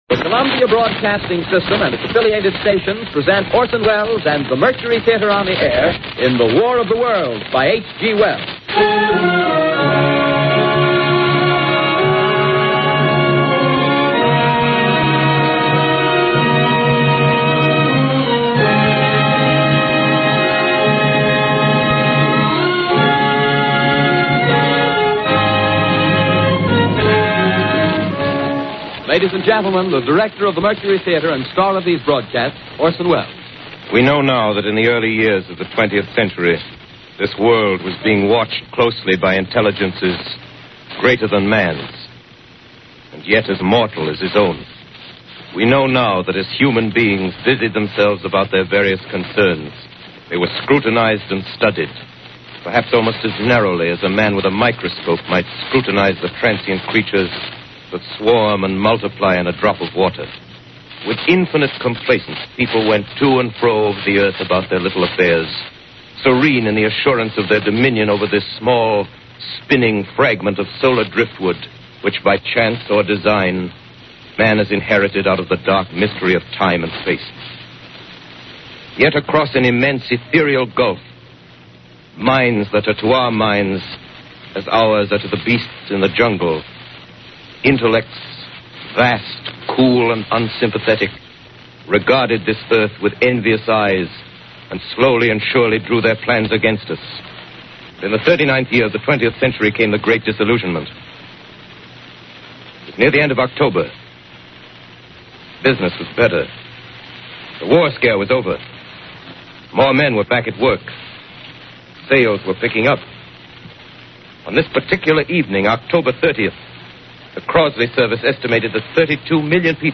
Orson Welles' famous "The War of the Worlds" broadcast changed the world of radio.
There isn't a perfect copy of an audio recording of The War of the Worlds around, but the ones included below are pretty good, and the quality actually gets better once you get a few minutes into the production.
Pay attention to Welles' tone - it's almost playful, giving no hint of the drama to come.